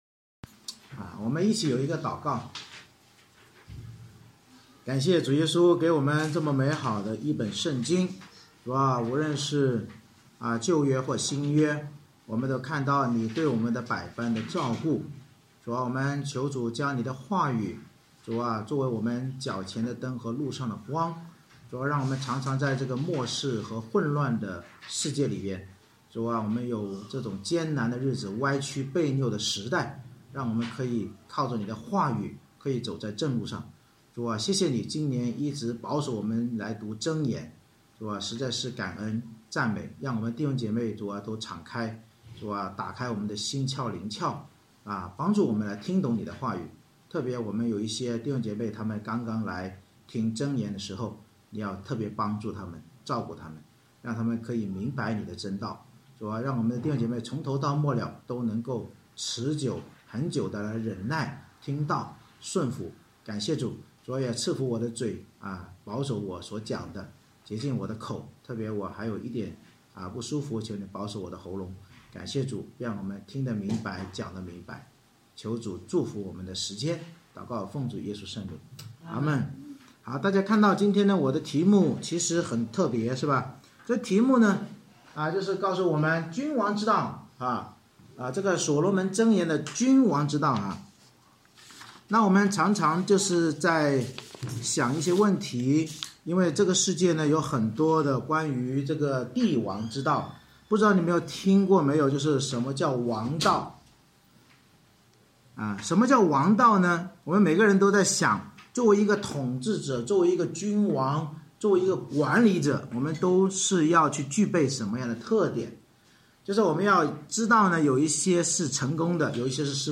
箴言25章 Service Type: 主日崇拜 通过希西家的人所誊录的所罗门箴言，教导我们要成为拥有君王之道的智慧人，若懂得何事要隐藏何事要查清并有爱仇敌之心就必得主奖赏。